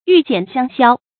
玉減香消 注音： ㄧㄩˋ ㄐㄧㄢˇ ㄒㄧㄤ ㄒㄧㄠ 讀音讀法： 意思解釋： 見「玉減香銷」。